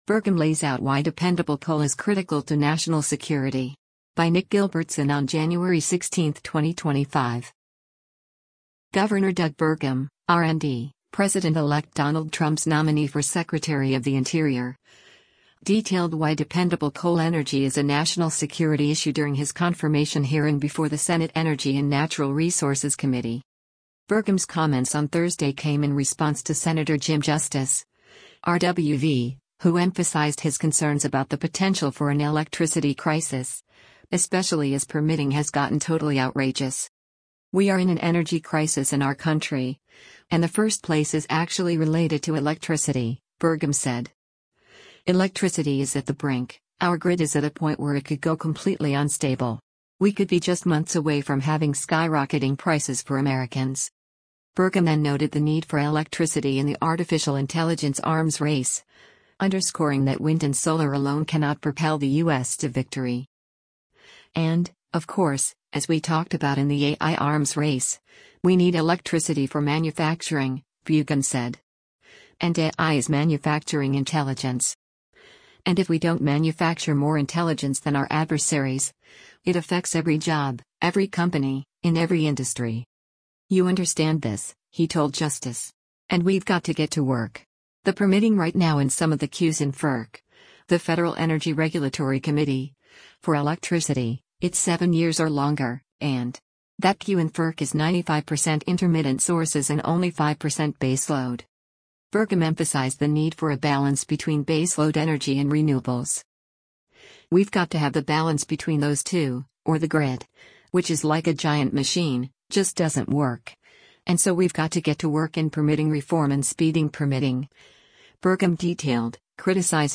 Gov. Doug Burgum (R-ND), President-elect Donald Trump’s nominee for Secretary of the Interior, detailed why dependable coal energy is a national security issue during his confirmation hearing before the Senate Energy and Natural Resources Committee.